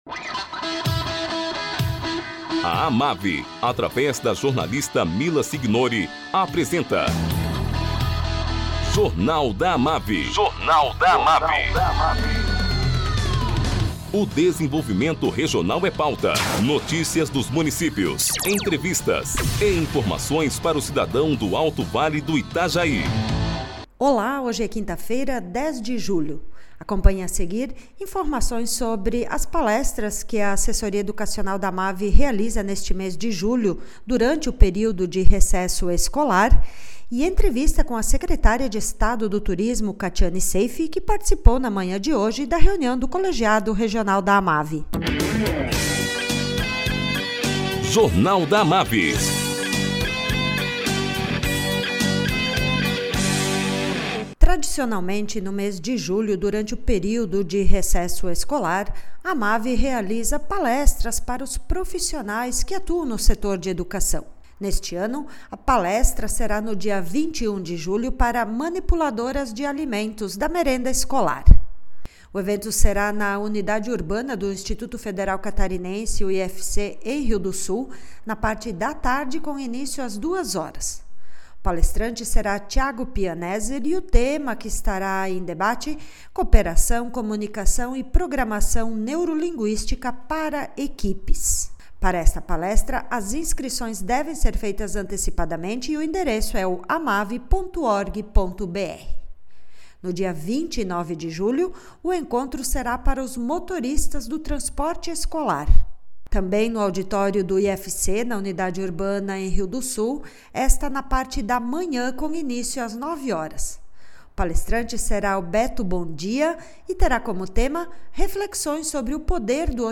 Entrevista com a Secretária de Estado do Turismo, Catiane Seif, que participou na manhã de hoje da reunião do Colegiado de Turismo da AMAVI.